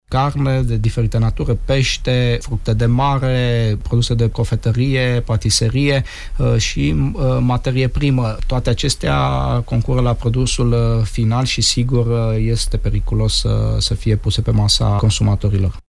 Comisarul șef, Sorin Susanu: